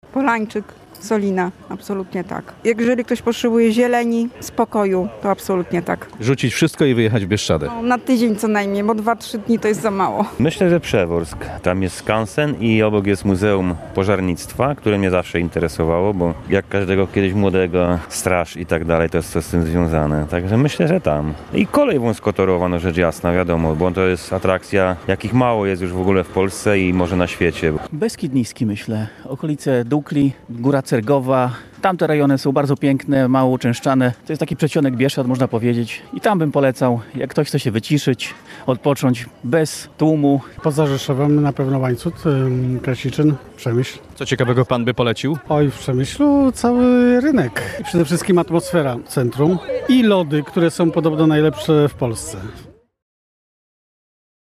Co warto zobaczyć na Podkarpaciu? Polecają mieszkańcy Rzeszowa (SONDA)